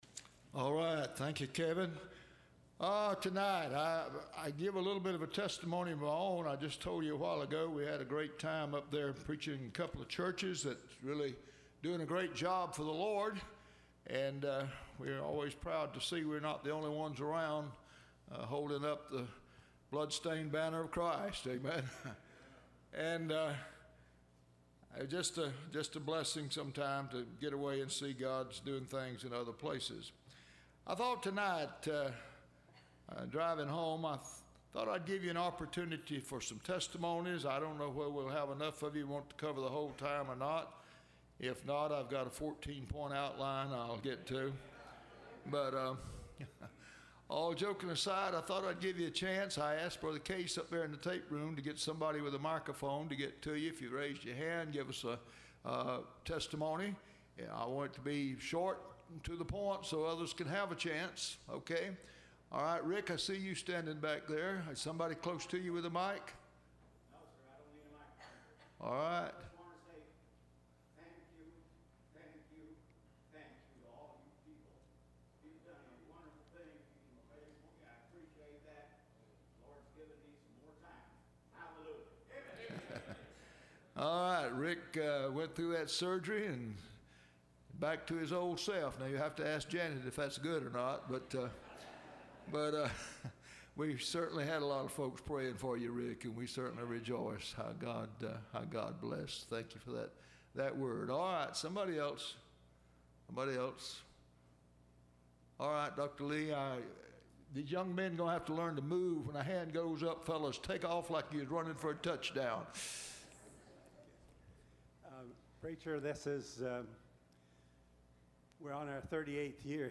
Testimonies – Landmark Baptist Church
Service Type: Wednesday Church